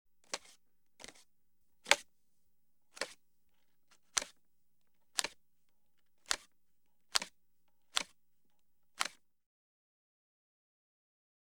household
Coin Movement in Hand